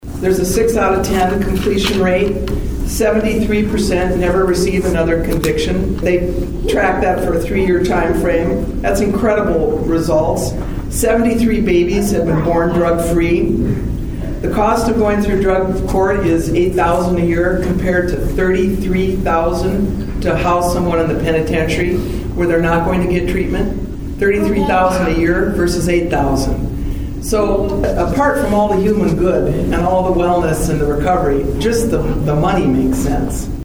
South Dakota Supreme Court Justice Janine Kern speaks at the Sixth Circuit Drug and DUI Treatment Court Program Graduation in Pierre Nov. 12, 2025.